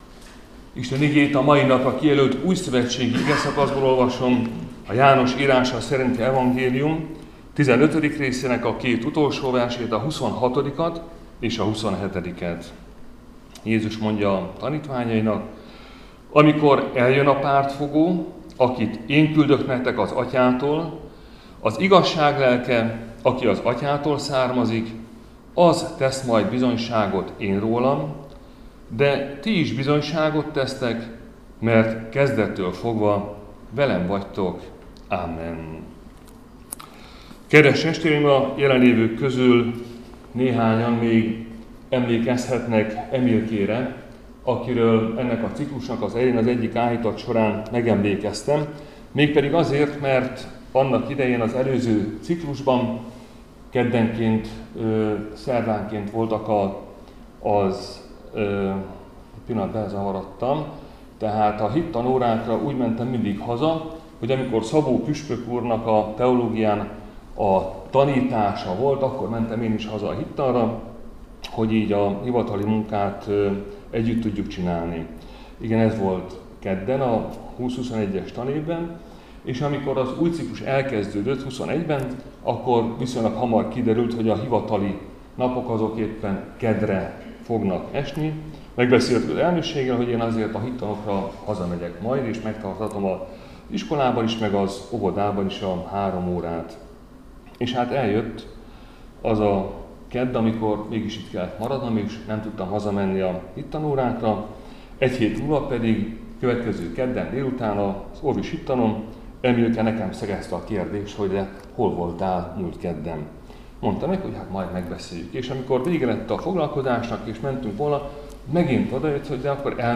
Áhítat, 2026. március 17.